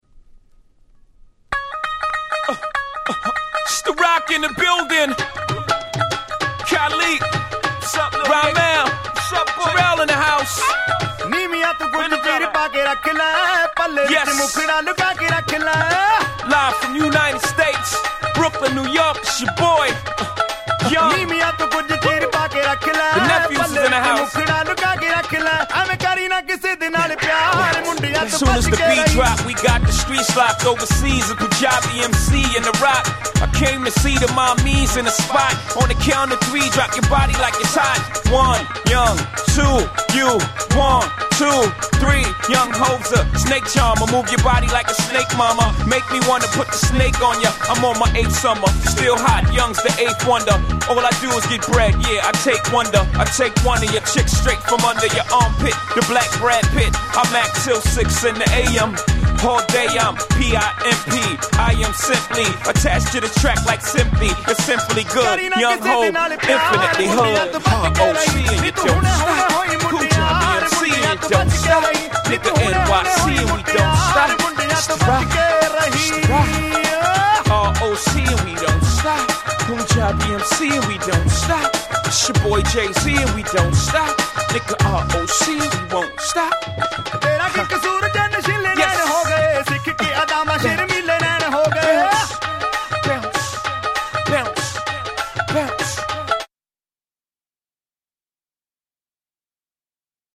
超絶Club Hit 00's Hip Hop !!!!!